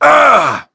Shakmeh-Vox_Damage_05.wav